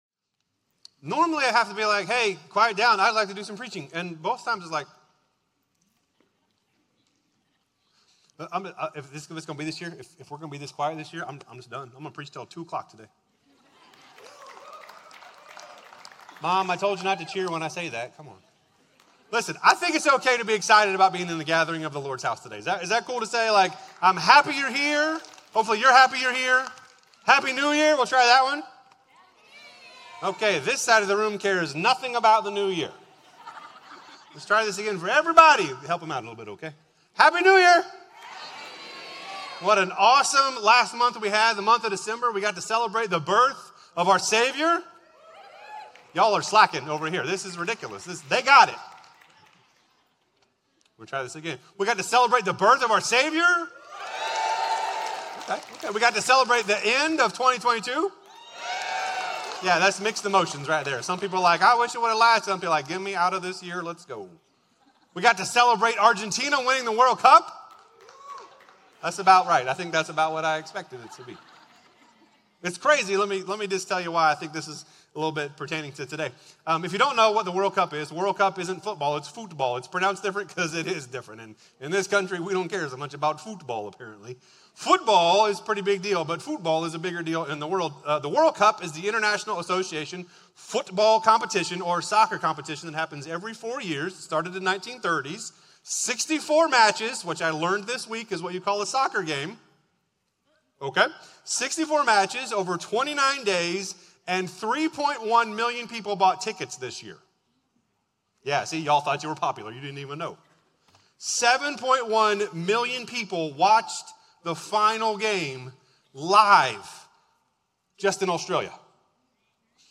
Crossroads Community Church - Audio Sermons